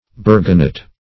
Search Result for " burganet" : The Collaborative International Dictionary of English v.0.48: Burganet \Bur"ga*net\, n. See Burgonet .